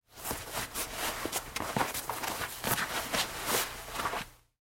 Звуки кожаных перчаток
Натягивают на руку